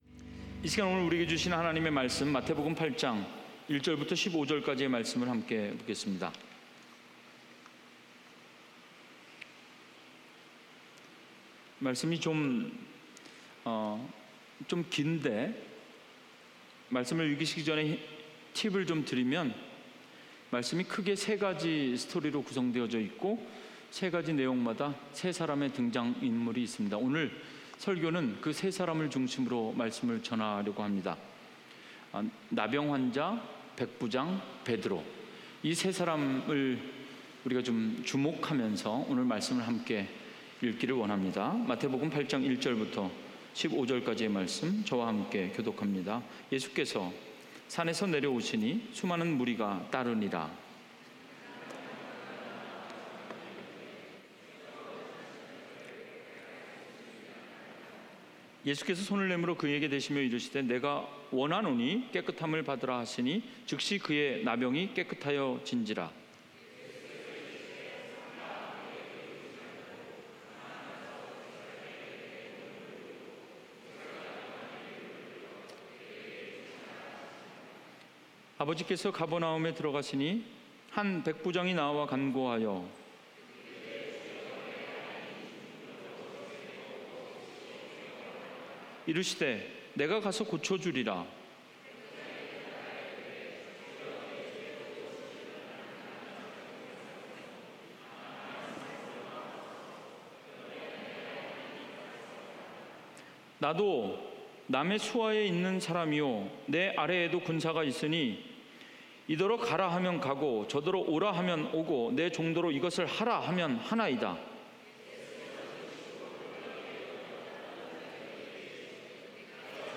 주일설교 - 2019년 07월28일 - 다만 말씀으로만 하옵소서 (But just say the word)